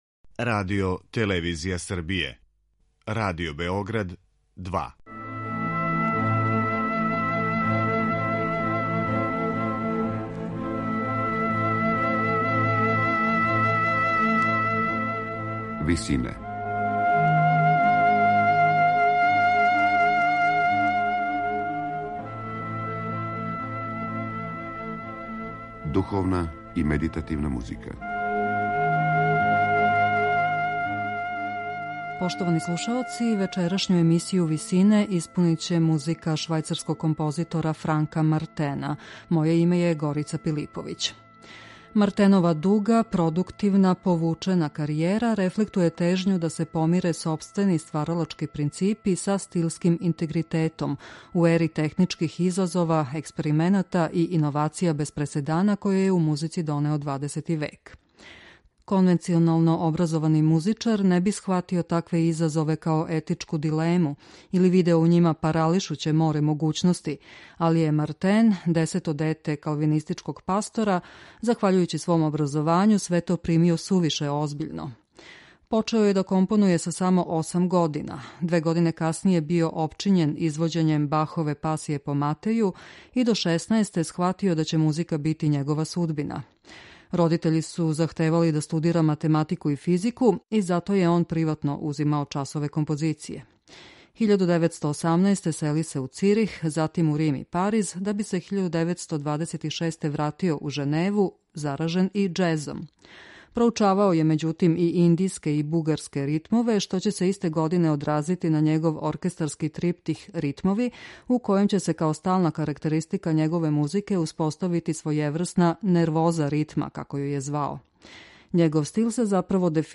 ораторијум